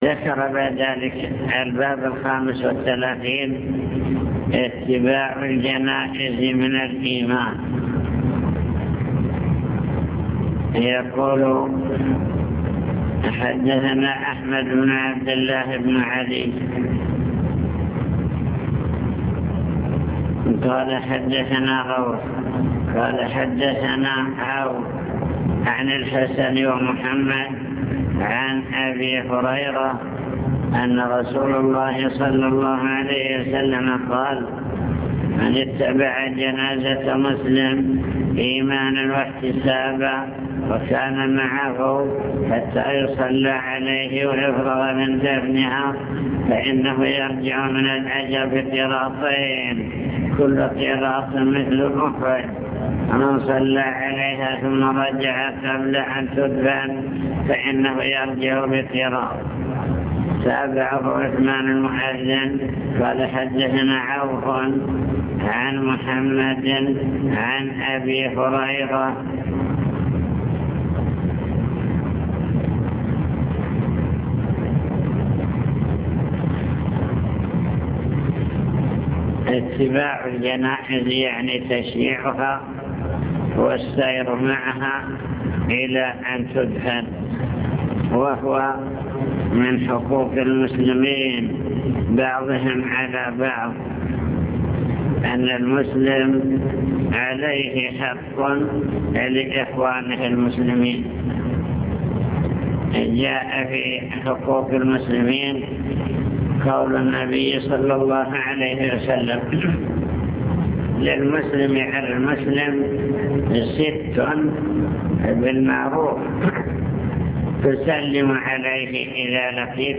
المكتبة الصوتية  تسجيلات - كتب  شرح كتاب الإيمان من صحيح البخاري